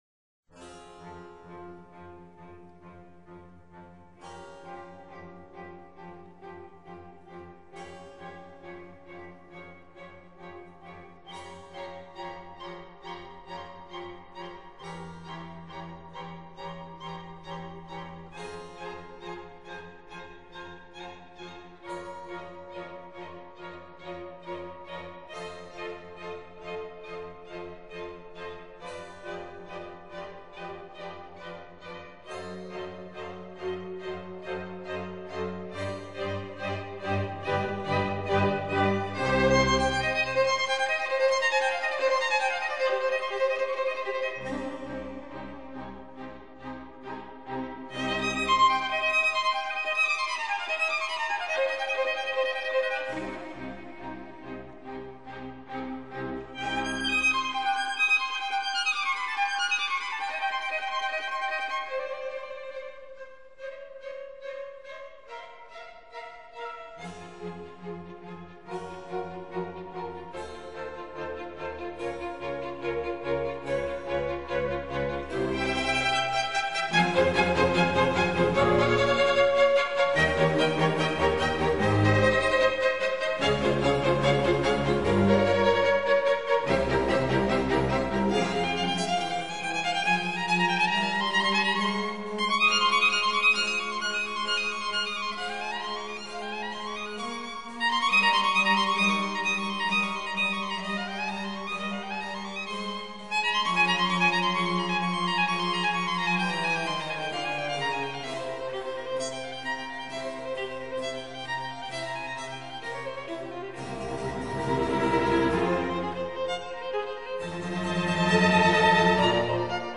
冬 F小调 第一乐章：不很快的快板